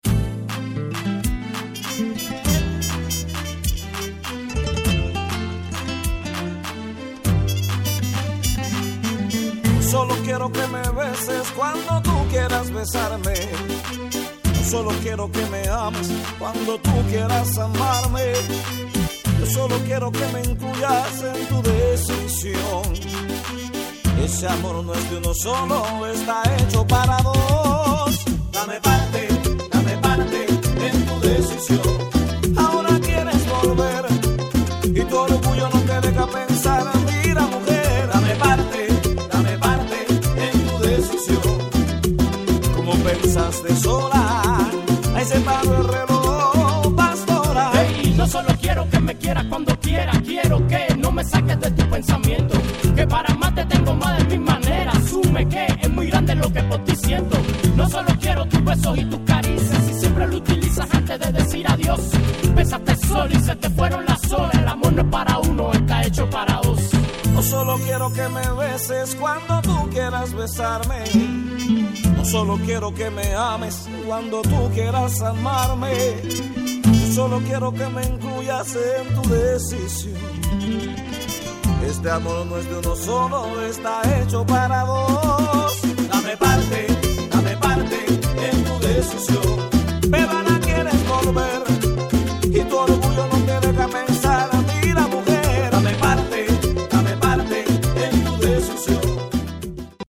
• Latin/Salsa/Reggae